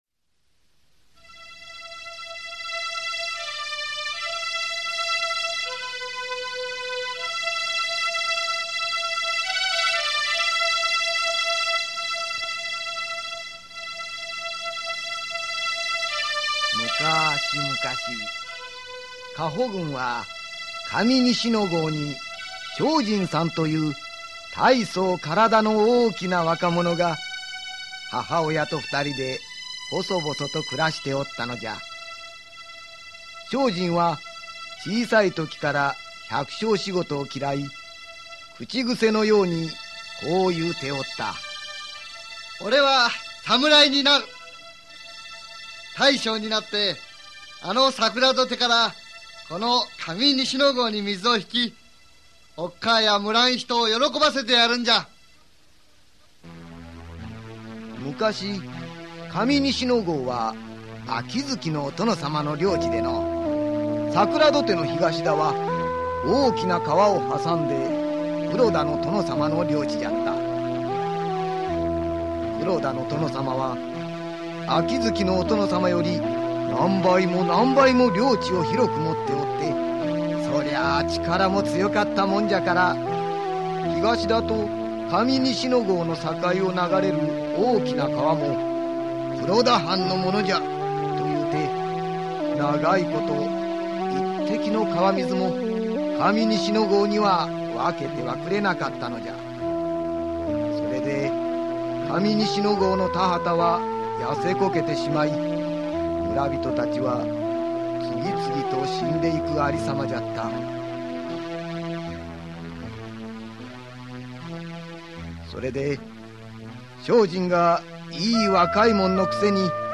[オーディオブック] 一くわぼりのショージンさん